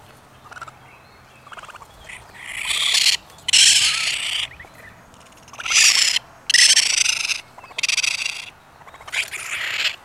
Raccoon Babies
This little fellow was not happy to be on the scale and let us know about it!